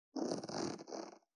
420,ジッパー,チャックの音,洋服関係音,ジー,バリバリ,カチャ,ガチャ,シュッ,
ジッパー効果音洋服関係